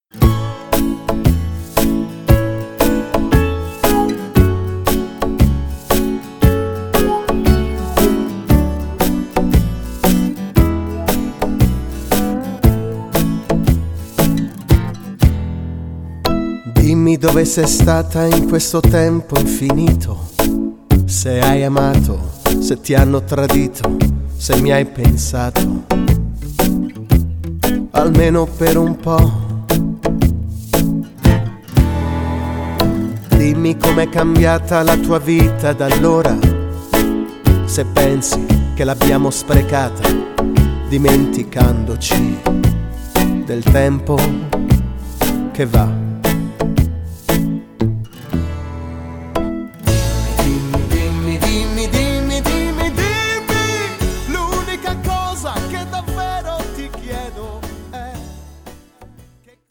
Slow Fox
12 Brani Editoriali + una cover di ballabili per Orchestra